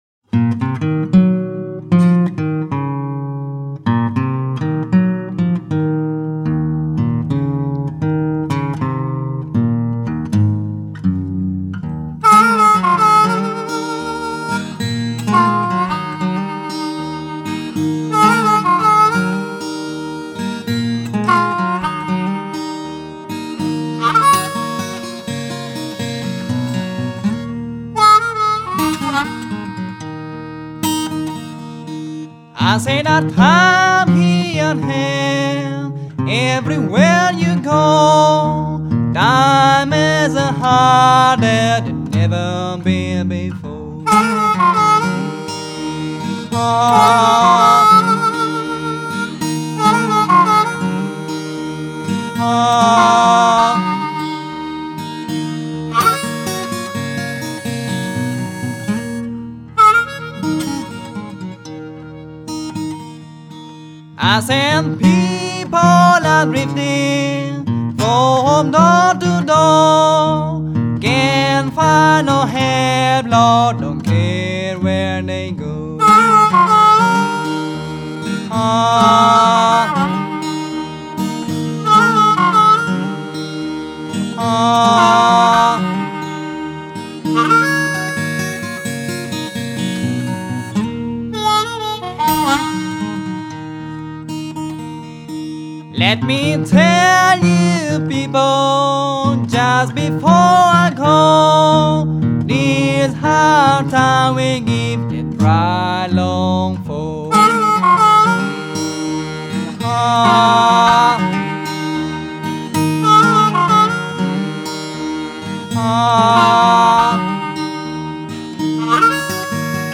guitar & vocals
harp